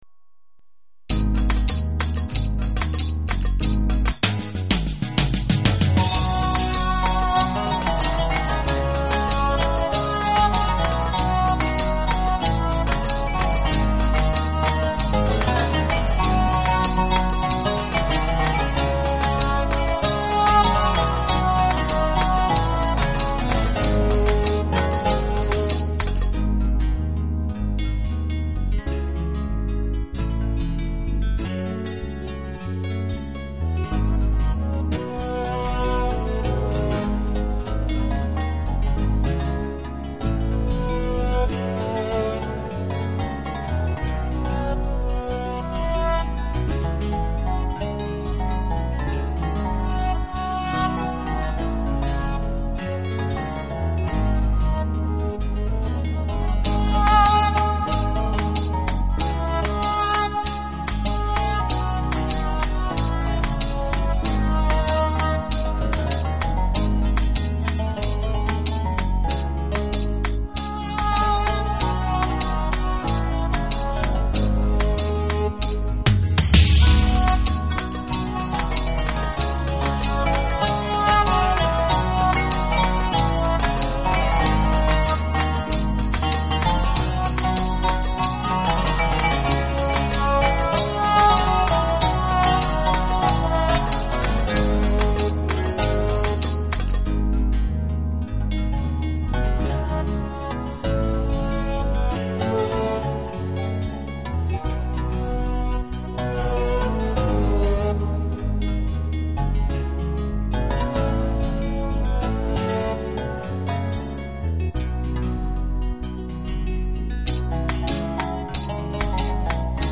Deneme amaçlı nostaljik bir çalışmam...